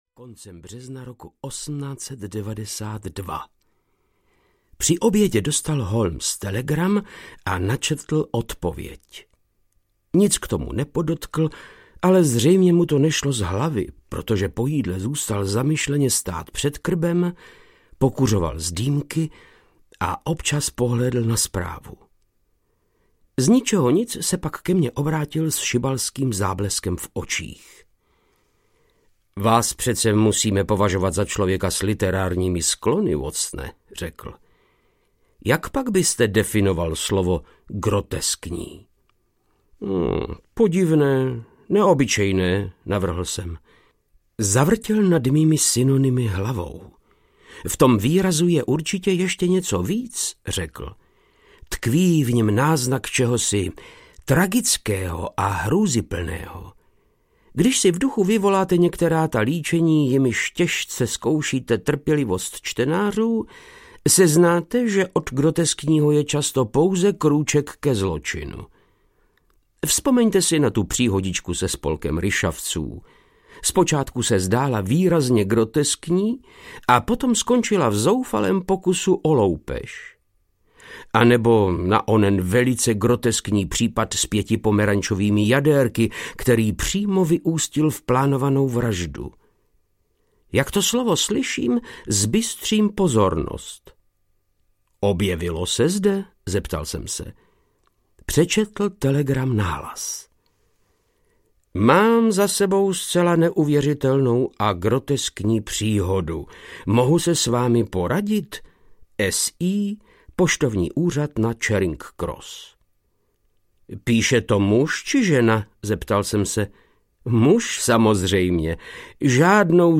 Velká sbírka příběhů Sherlocka Holmese audiokniha
Ukázka z knihy
• InterpretVáclav Knop